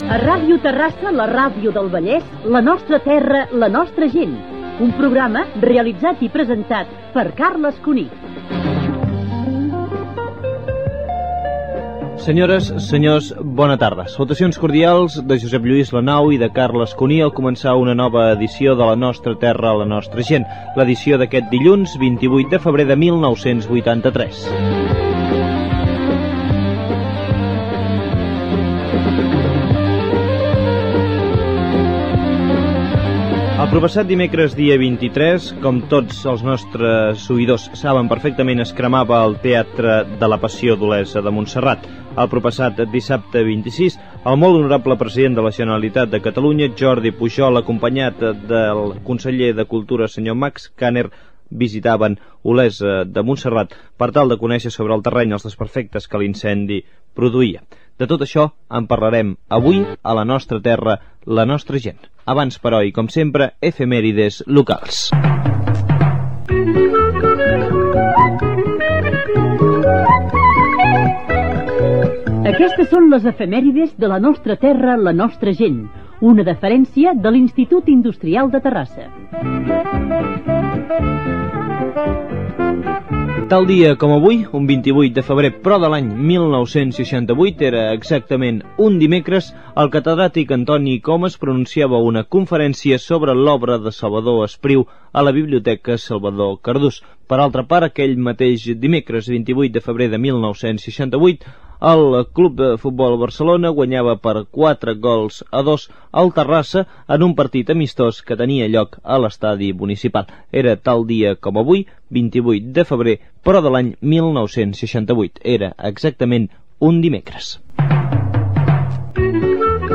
Careta del programa, començament del programa amb els noms de l'equip i la data, sumari i efemèrides locals.